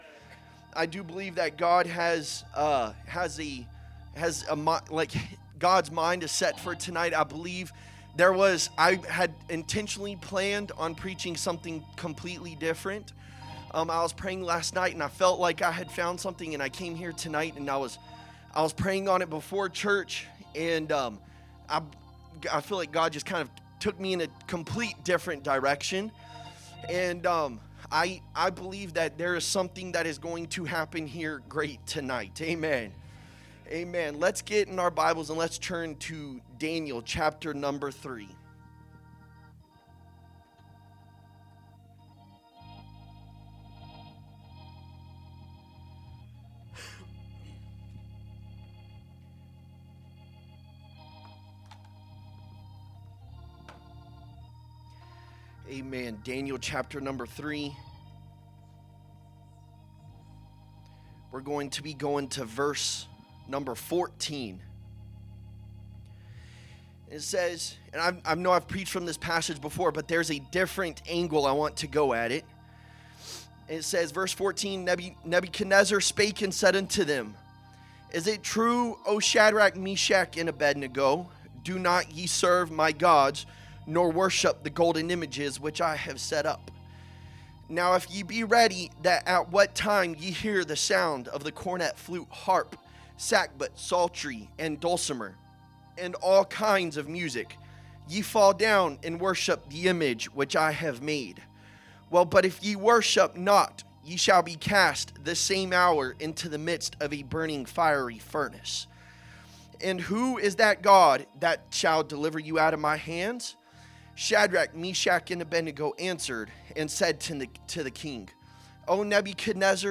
A message from the series "Guest Speakers."